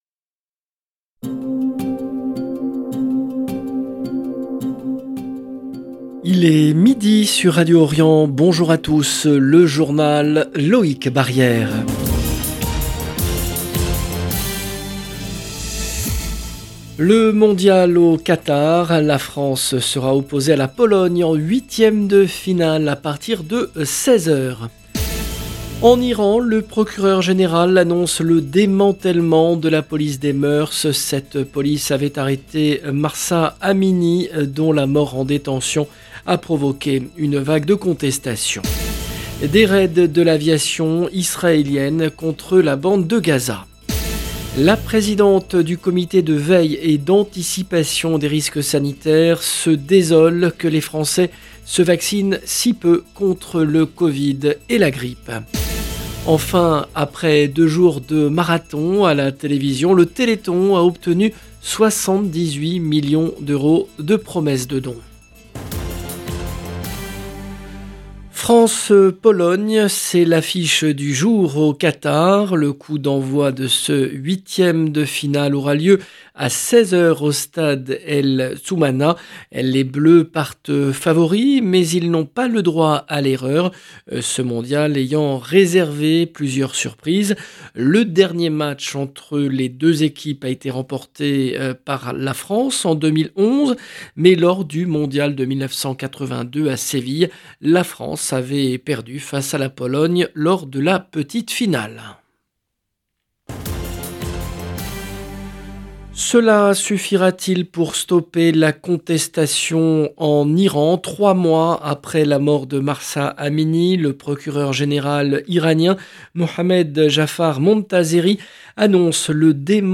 LE JOURNAL DE MIDI EN LANGUE FRANCAISE DU 4/12/22